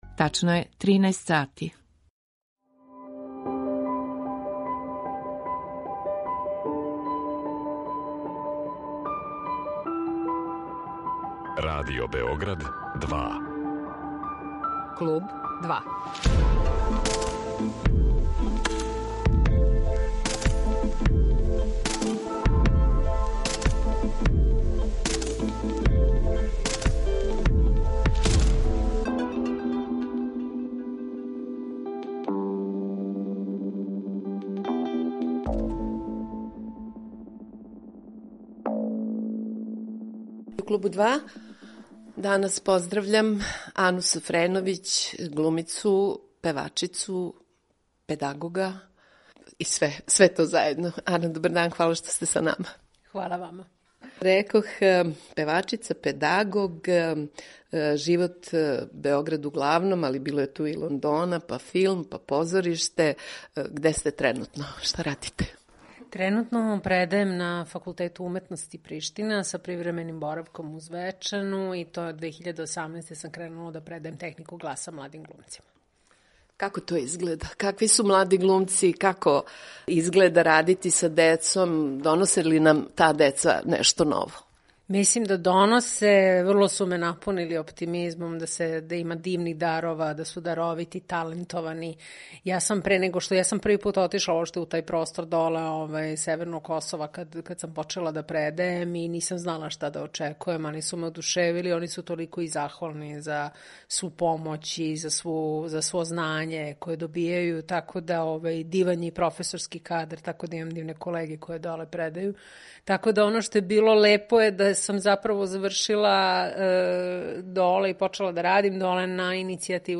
Филмска и позоришна глумица, џез певачица истанчаног нерва и гласа, наша Енглескиња Ана Софреновић, гошћа је Клуба 2.